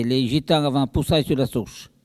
Elle provient de Saint-Urbain.
Catégorie Locution ( parler, expression, langue,... )